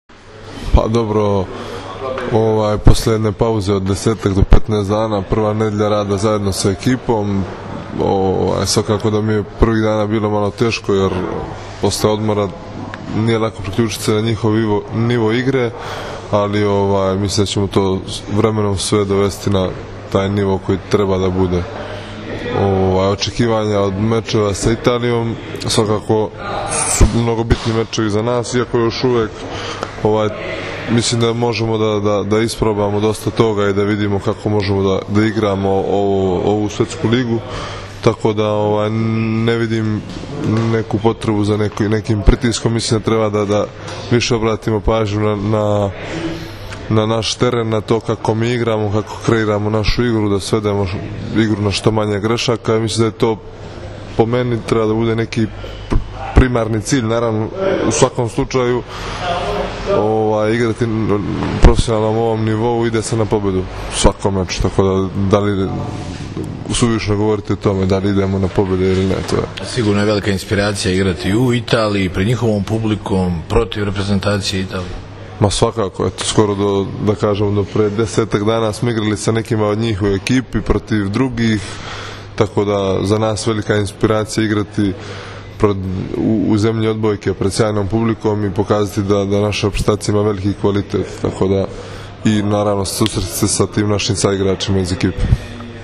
U Pezaru je danas održana konferencija za novinare uoči prve utakmice II vikenda A grupe XXVI Svetske lige 2015. između Srbije i Italije, kojoj su prisustvovali kapiteni i treneri obe selekcije: Dragan Stanković i Nikola Grbić, odnosno Dragan Travica i Mauro Beruto.
IZJAVA NEMANJE PETRIĆA